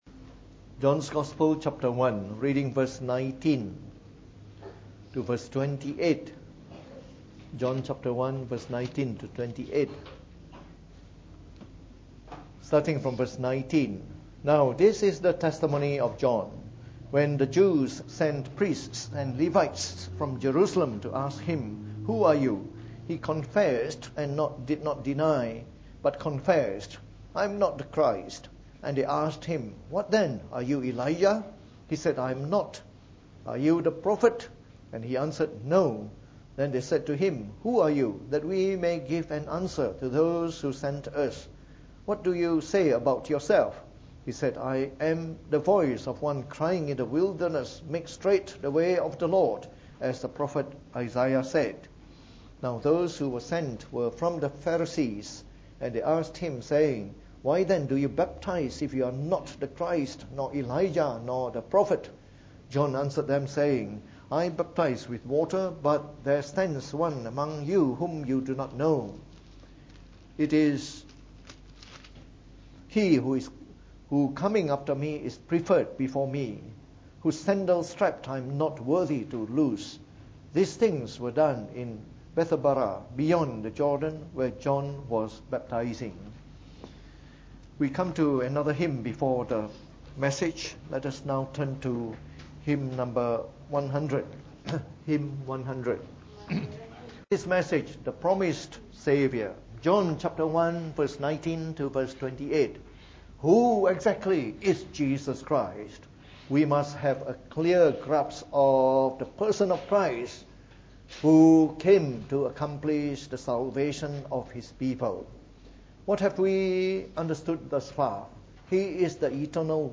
From our series on the Gospel of John delivered in the Evening Service.